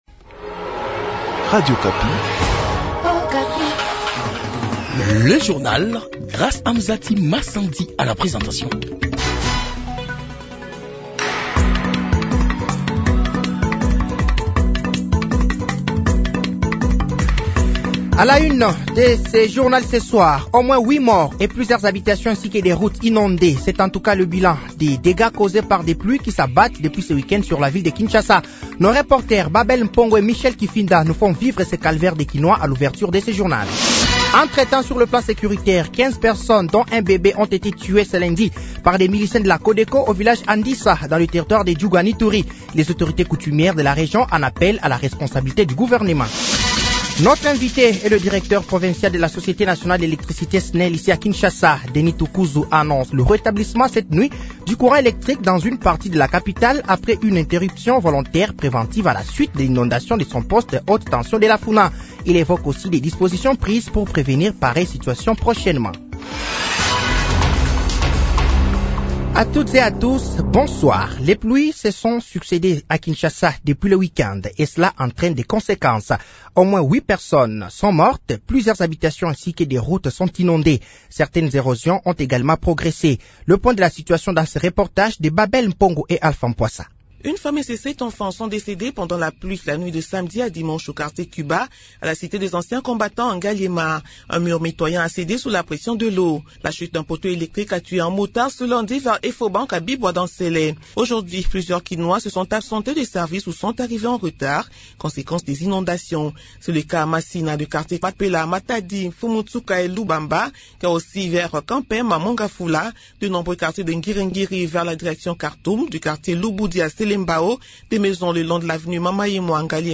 Journal Soir
Journal français de 18h de ce lundi 8 avril 2024